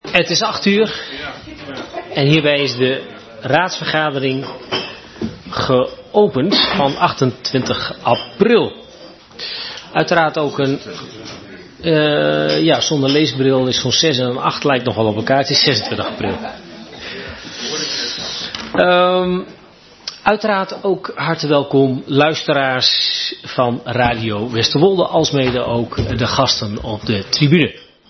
Geluidsverslag raadsvergadering 26 april 2023